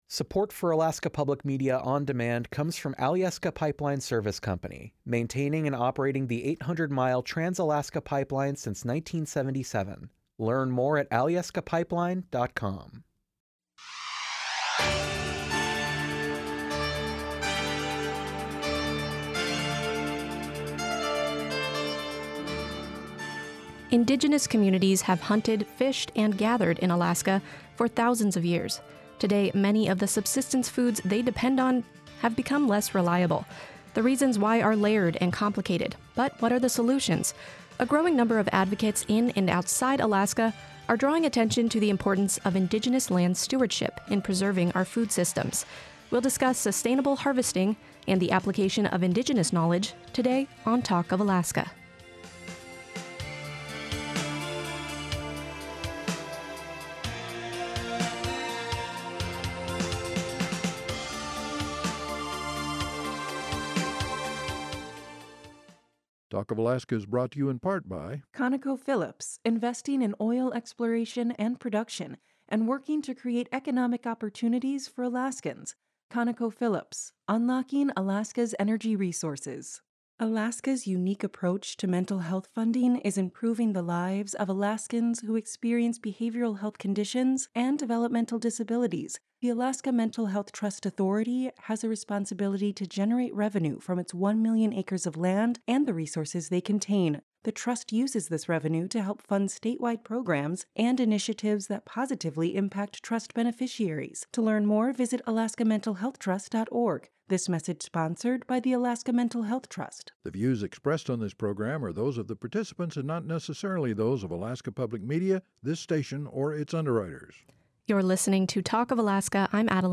during the live broadcast.